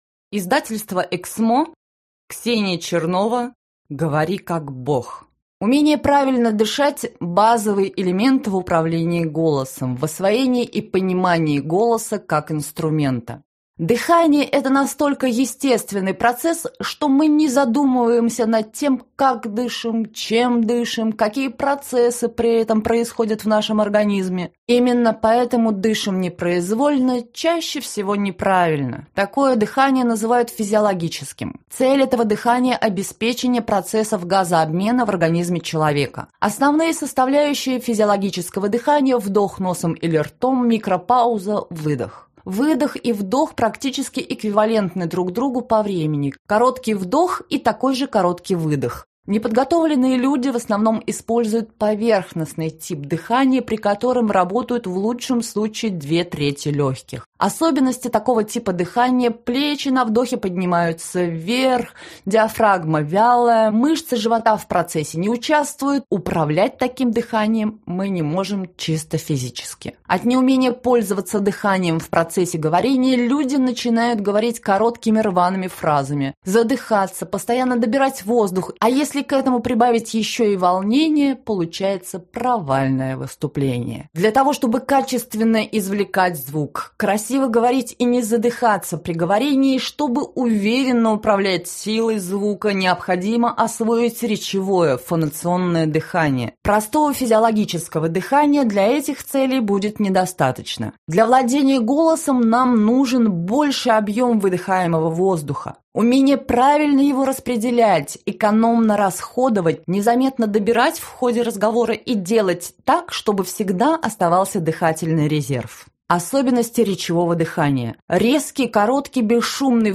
Читает аудиокнигу